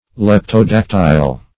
Search Result for " leptodactyl" : The Collaborative International Dictionary of English v.0.48: Leptodactyl \Lep`to*dac"tyl\ (l[e^]p`t[-o]*d[a^]k"t[i^]l), n. [Gr. lepto`s small, thin + da`ktylos finger, toe.]